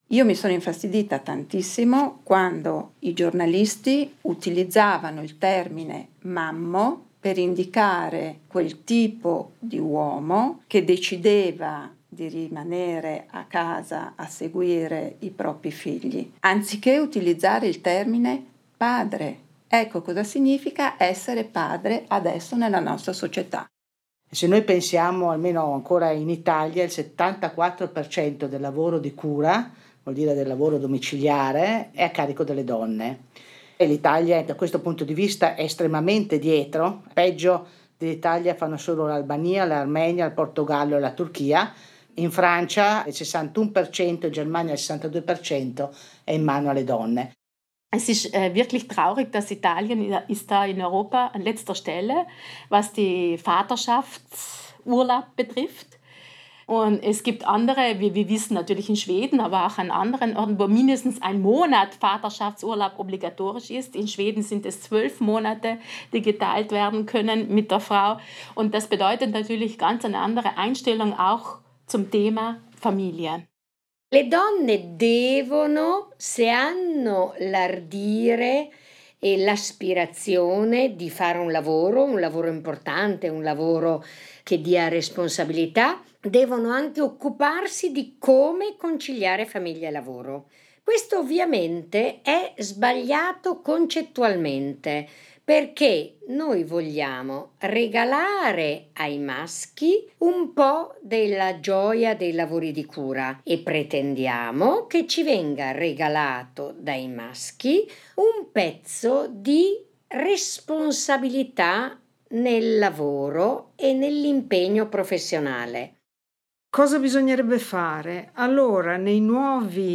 Dreißig Frauen erzählen und reflektieren in einem vielstimmigen Chor über ihre Arbeit und die Ziele, für die sie gekämpft haben und weiterhin kämpfen.